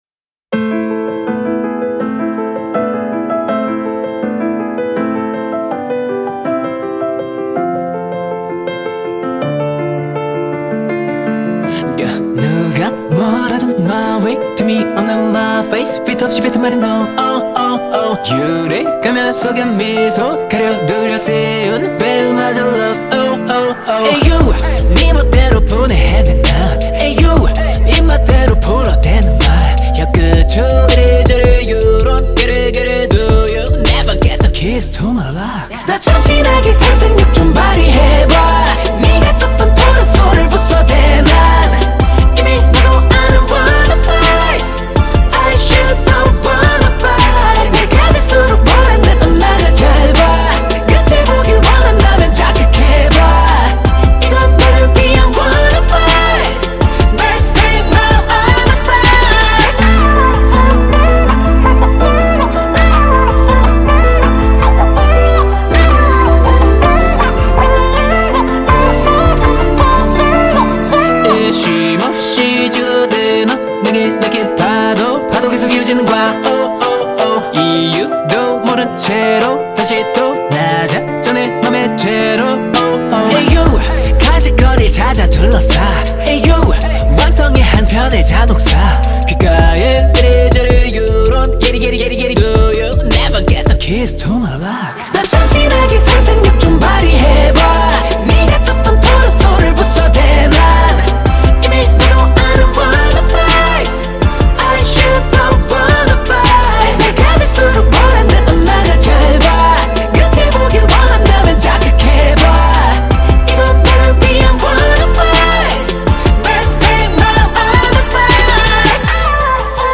سبک: پاپ